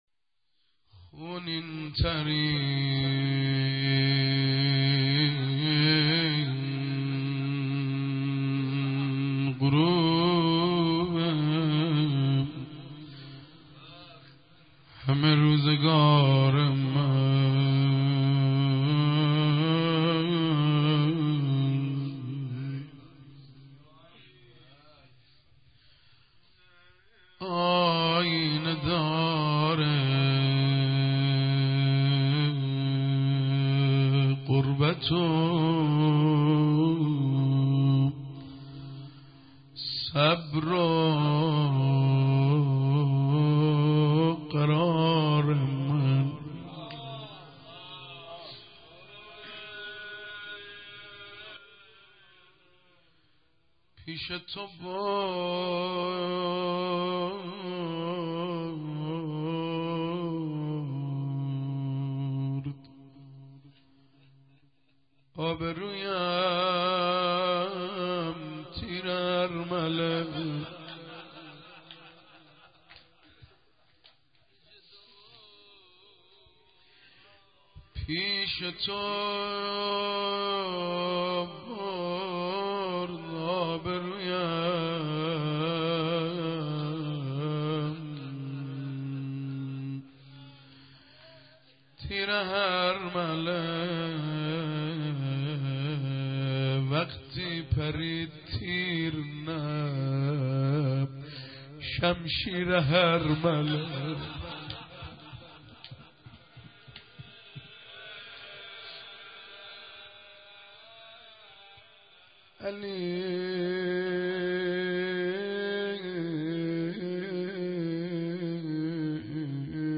مداحی مداحان اهل بیت(ع)/روز نوزدهم محرم95/تالار قصر کلاسیک